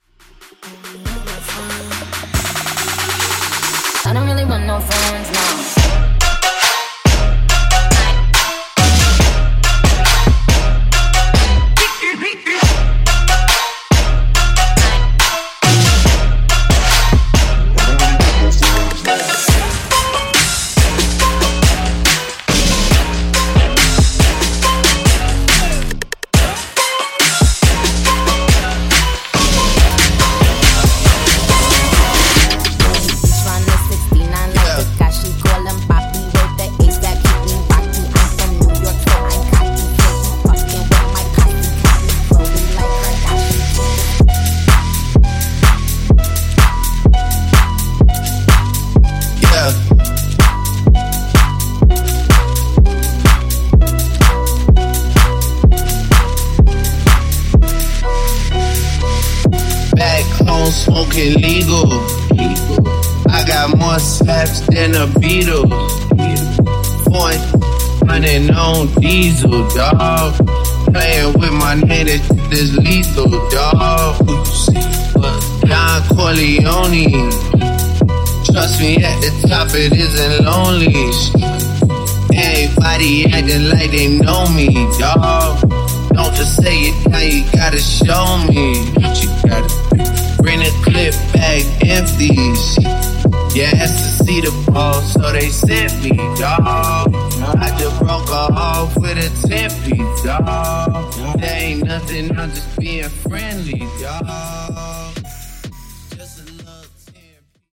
[70-126 BPM]Date Added